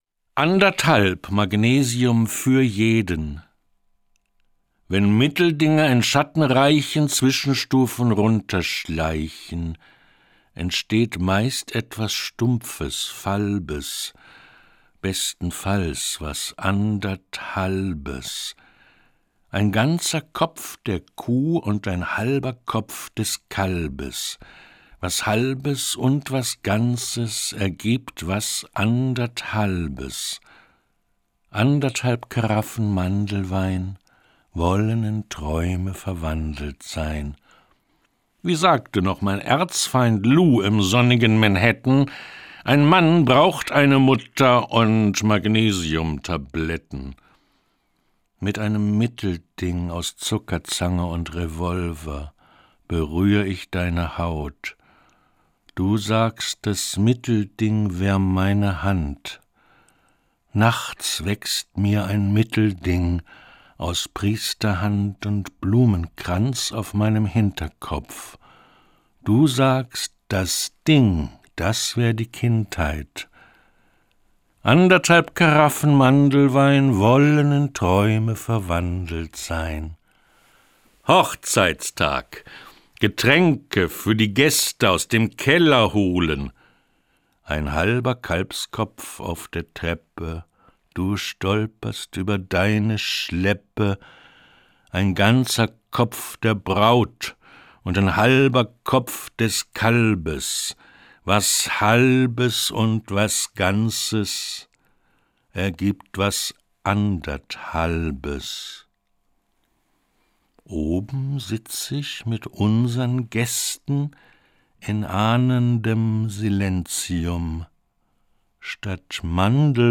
Das radio3-Gedicht der Woche: Dichter von heute lesen radiophone Lyrik.
Gelesen von Max Goldt.